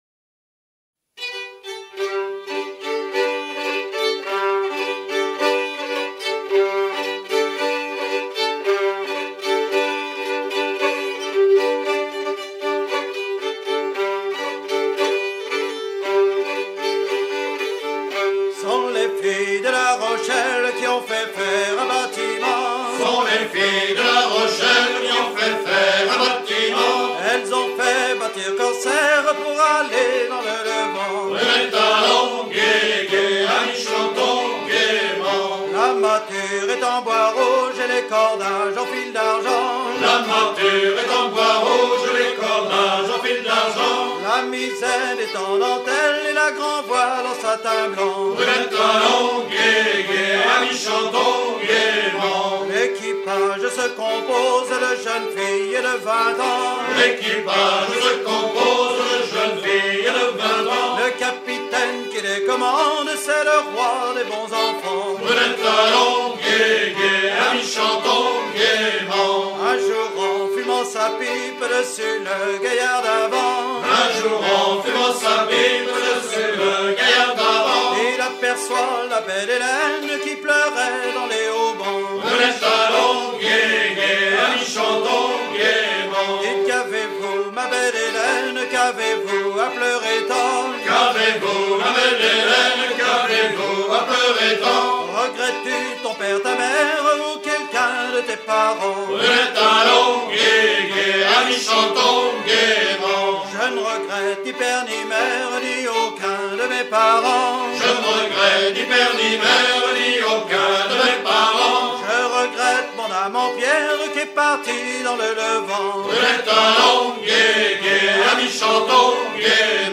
gestuel : à virer au cabestan
circonstance : maritimes
Genre laisse